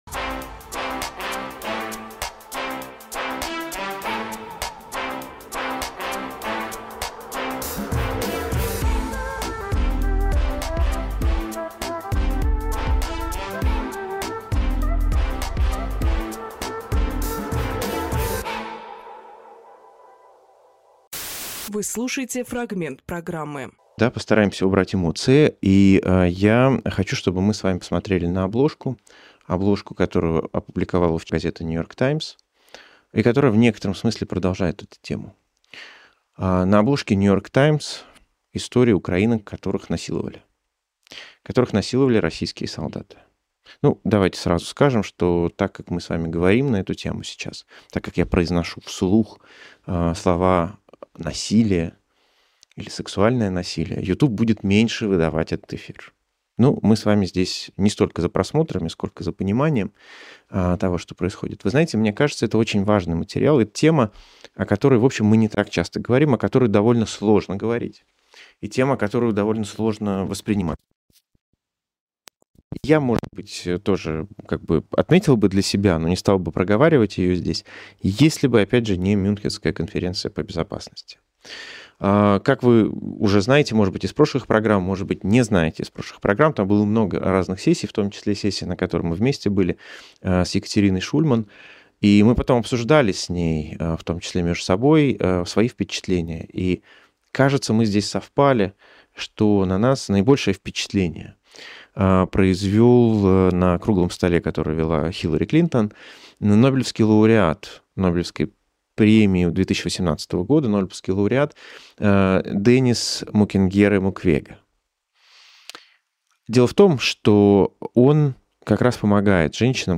Фрагмент эфира от 22.02.26